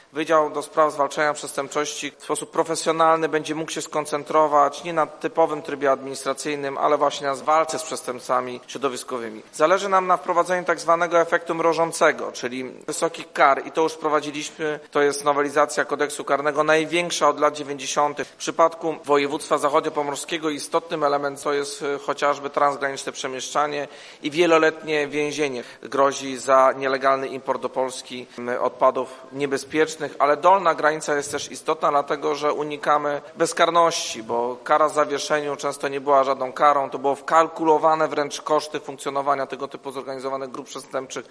W Zachodniopomorskim Urzędzie Wojewódzkim odbyła się inauguracja nowego Wydziału Zwalczania Przestępczości Środowiskowej w strukturach Wojewódzkiego Inspektoratu Ochrony Środowiska w Szczecinie.
Wydział Zwalczania Przestępczości Środowiskowej w sposób profesjonalny będzie mógł skoncentrować, nie na typowym trybie administracyjnym, ale na walce z przestępcami środowiskowymi – wyjaśnia wiceminister Klimatu i Środowiska Jacek Ozdoba